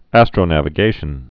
(ăstrō-năvĭ-gāshən)